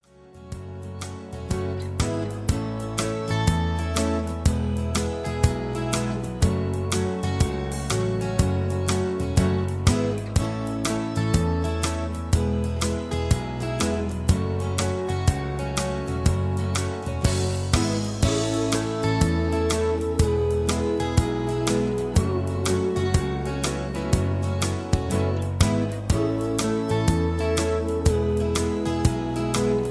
karaoke collection , backing tracks